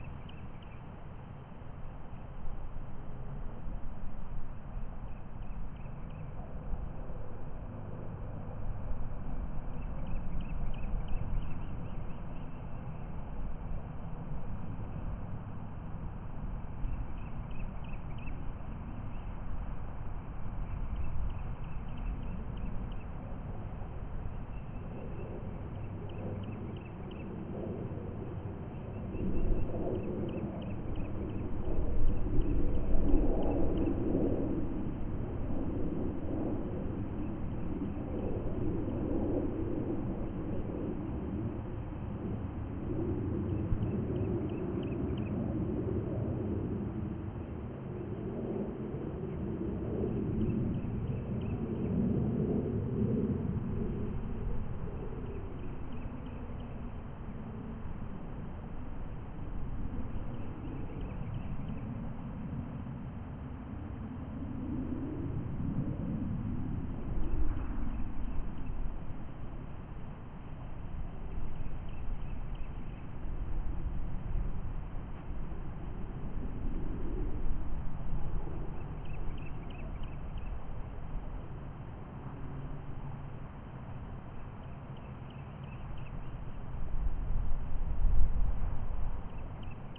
Noon Suburban Park Field Distant Birds Planes Windgusts ST450_ambiX.wav